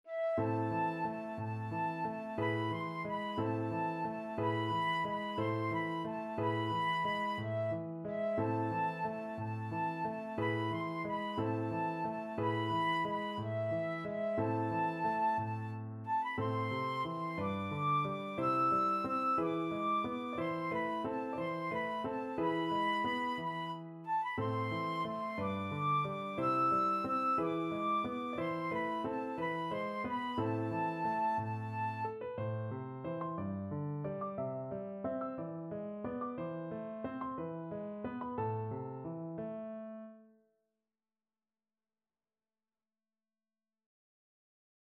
Classical Beethoven, Ludwig van Marmotte (8 Lieder, Op. 52, No. 7) Flute version
A minor (Sounding Pitch) (View more A minor Music for Flute )
6/8 (View more 6/8 Music)
~ = 90 Munter
Classical (View more Classical Flute Music)